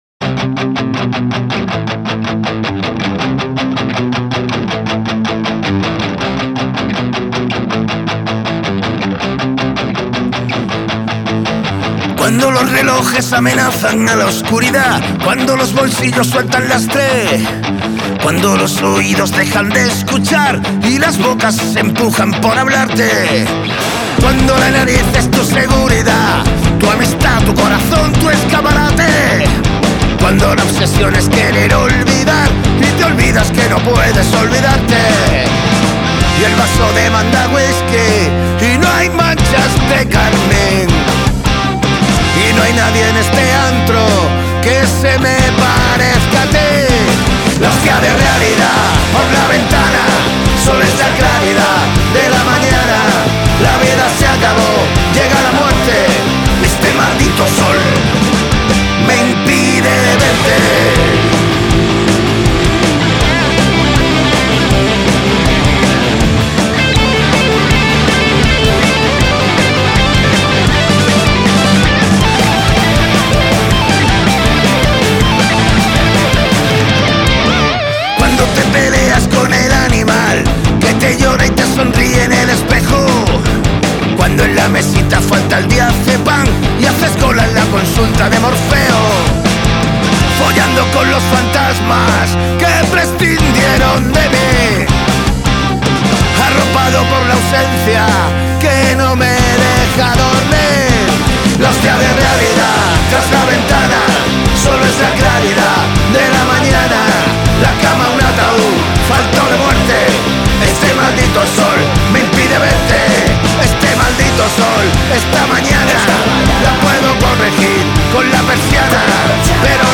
está formada por cinco músicos
voz
guitarra
bajo
batería